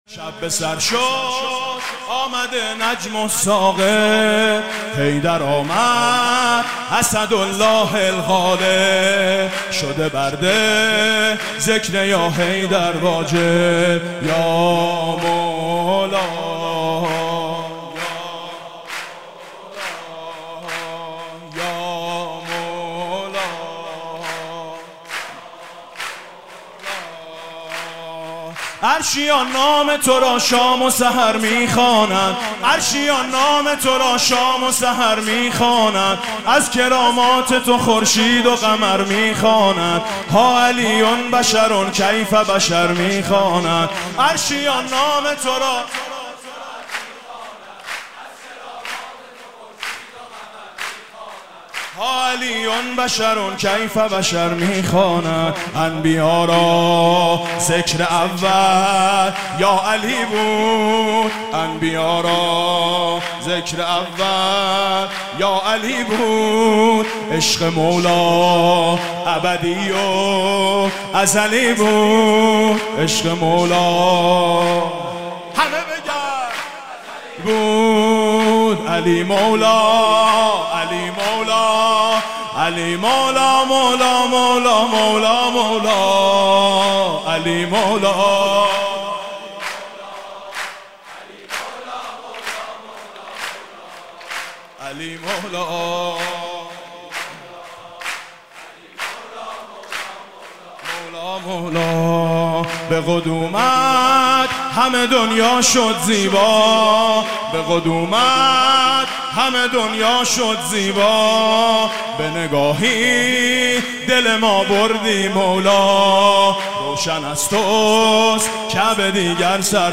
سرود: همسرِ فاطمه و یار پیمبر آمد